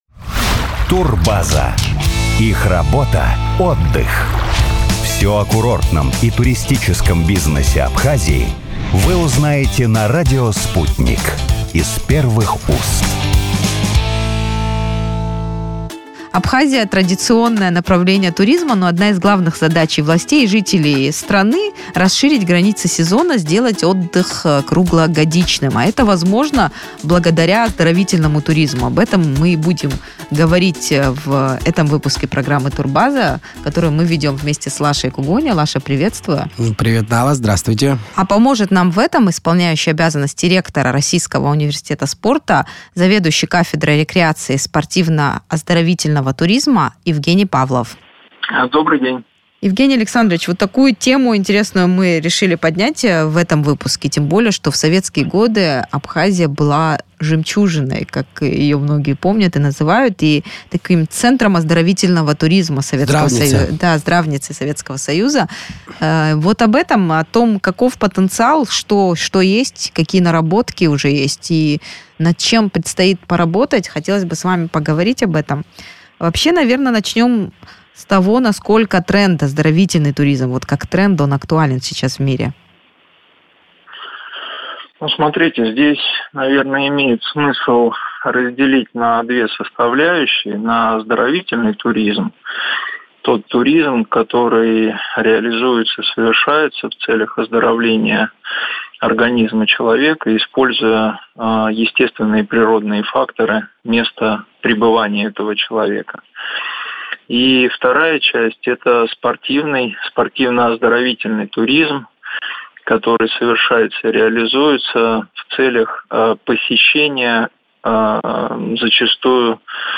в эфире радио Sputnik рассказал, как использовать возможности Абхазии для развития...